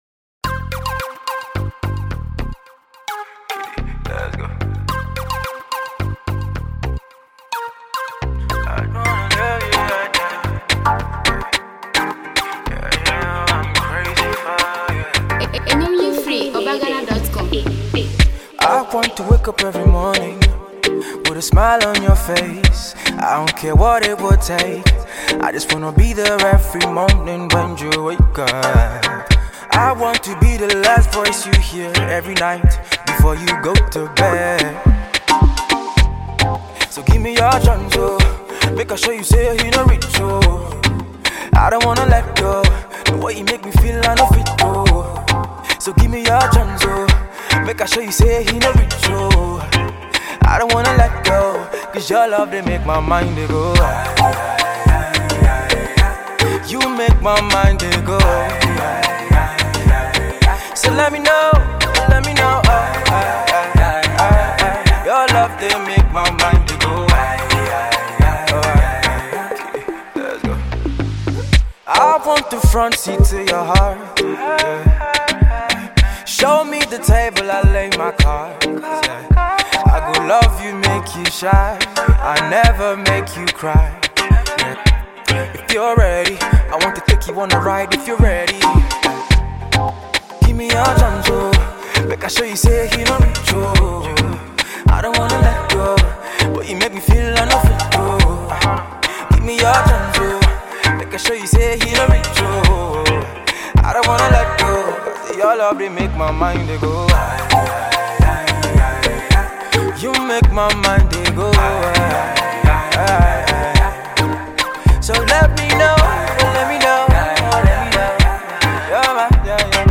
Ghana Music
female singer